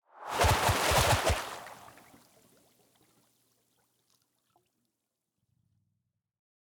Arrow v1.wav